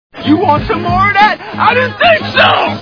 Billy Madison Movie Sound Bites